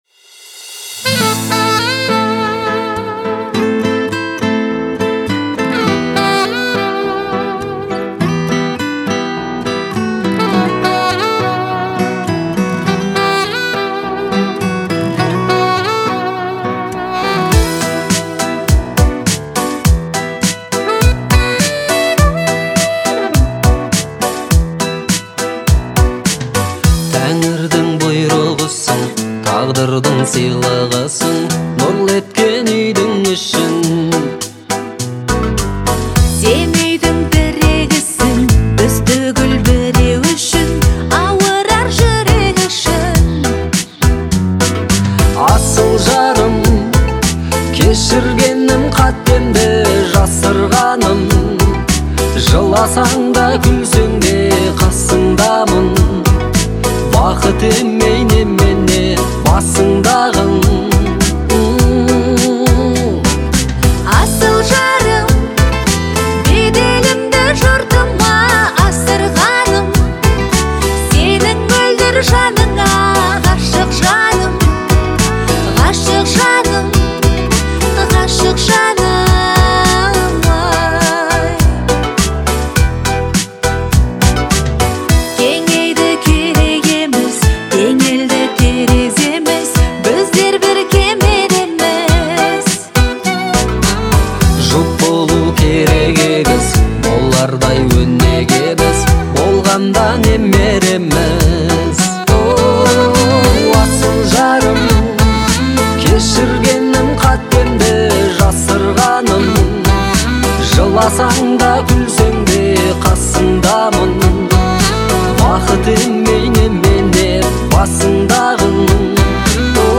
• Жанр: New Kaz / Казахские песни